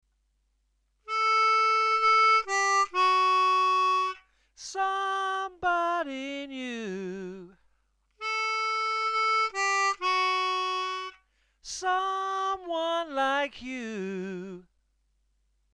6D   6B 5D..4D   3B   3B   3B..3B   (with hand vibrato)